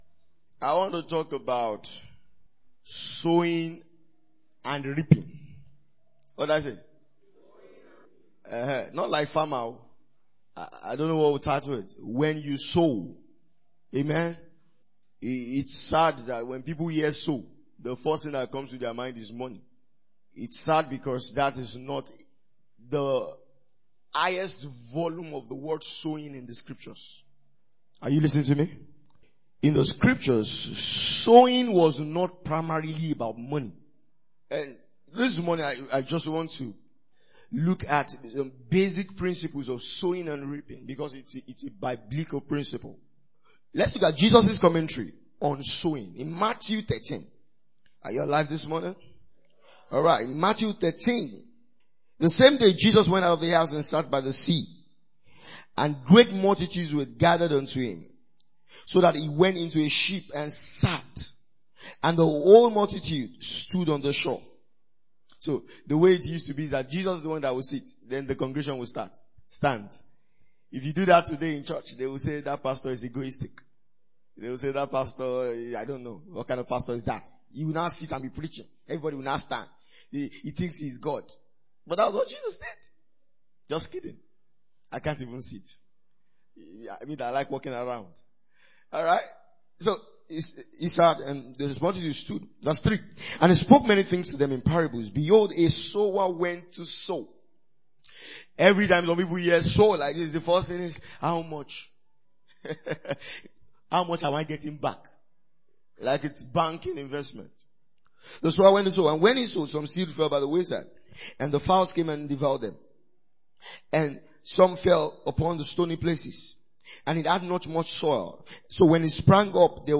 A teaching from our annual Glory Camp Meeting that sounds a note of caution to the believer as he goes about his work. It brings to fore the importance of sowing the right things, in the right places, and at the right time for a fruitful Christian walk.